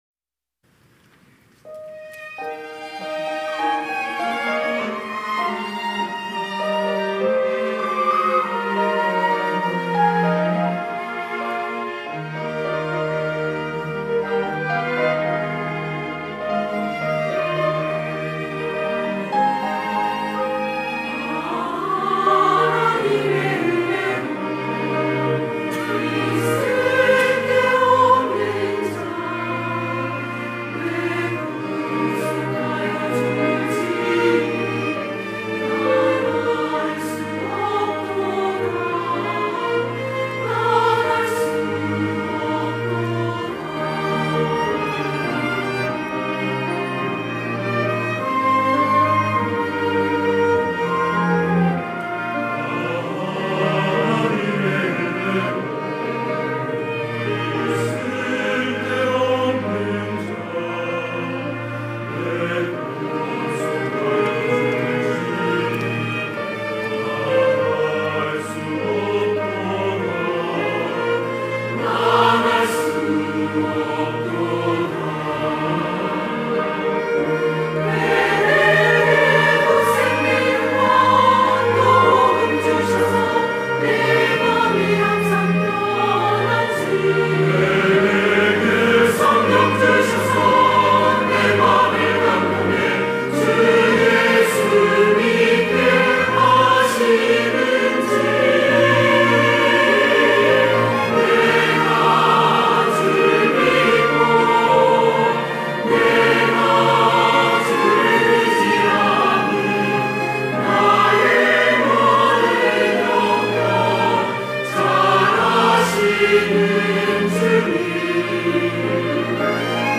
할렐루야(주일2부) - 아 하나님의 은혜로
찬양대